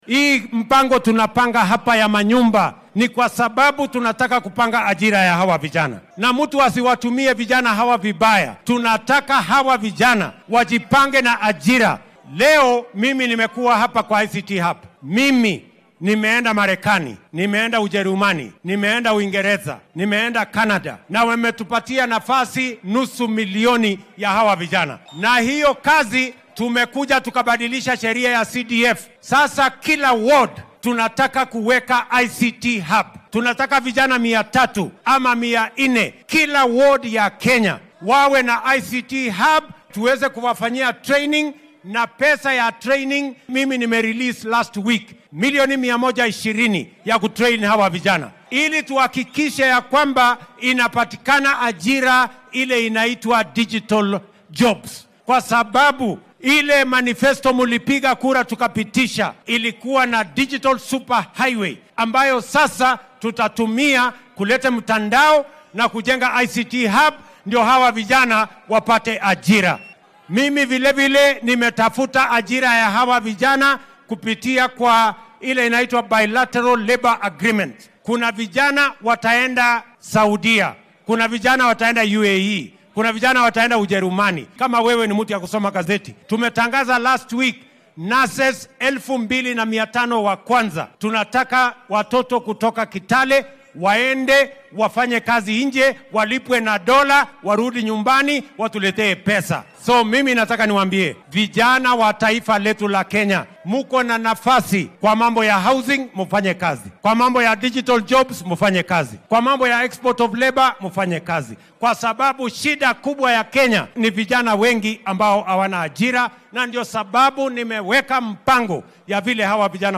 Arrintan ayuu madaxweyne William Ruto ka hadlay xilli uu  si rasmi ah magaalada Kitale ee ismaamulka Trans-Nzoia uga furay xarunta ICT-da ee bulshada.